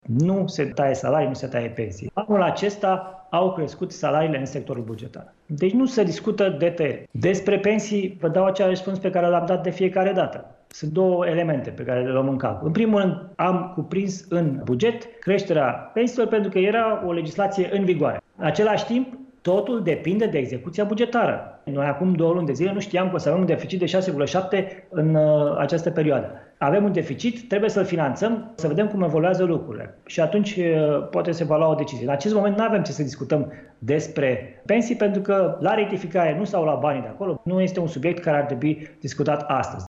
Totul depinde de cum va fi execuția bugetară, a precizat aseară la Realitatea Plus: